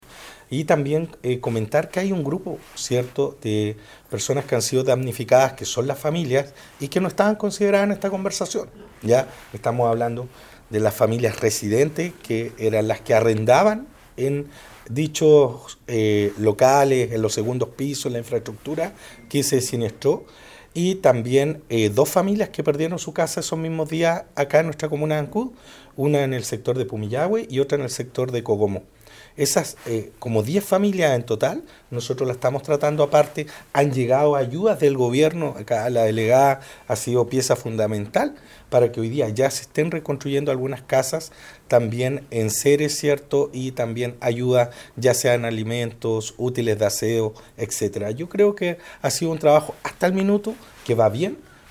En ese sentido, el alcalde de Ancud Andrés Ojeda se refirió a ello, indicando que también se ha hecho un esfuerzo para que puedan recibir los aportes necesarios para que puedan contar con un espacio propio y recomenzar sus vidas, luego de perder el inmueble que muchos de ellos arrendaban.